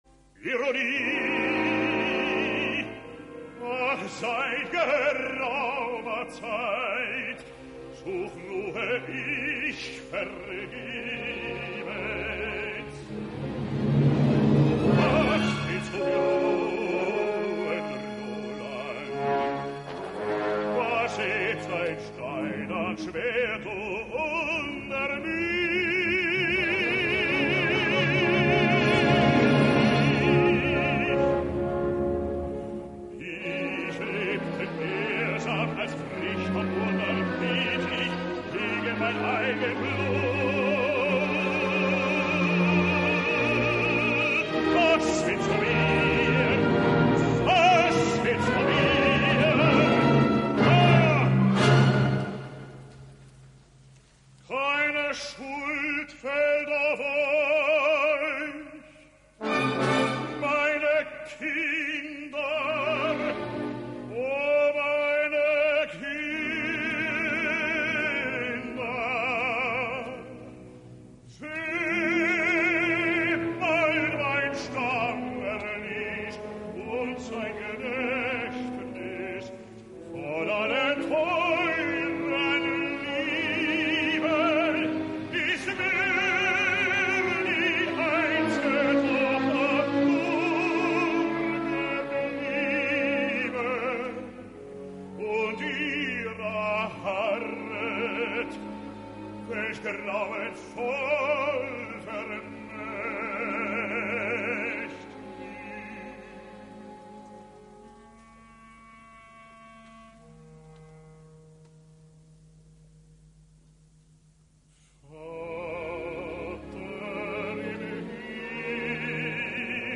historischer Verismo
Radioübertragung / Broadcast
Johannes Ratenow [Bariton]